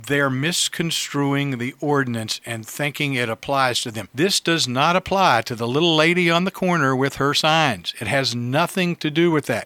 Mayor Ray Morriss talked to WCBC about the ordinance, saying people are not understanding its purpose…